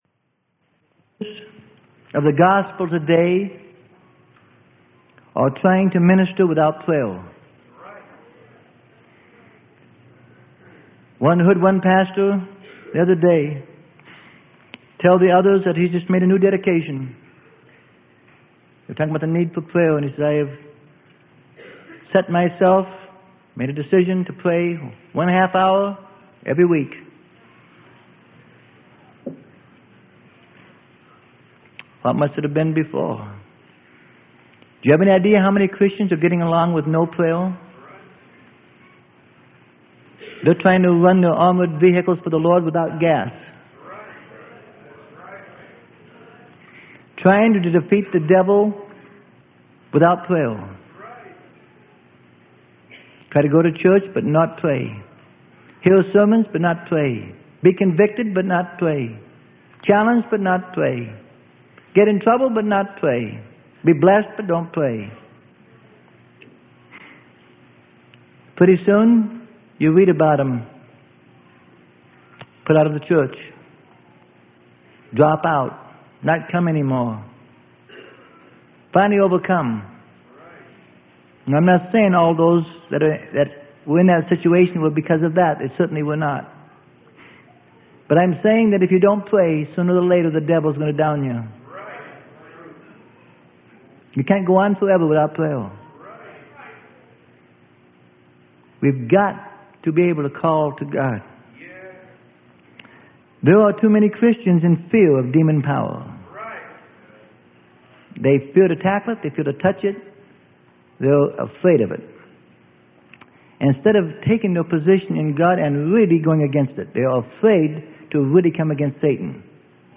Sermon: The Necessity of a Proper Prayer Life - Freely Given Online Library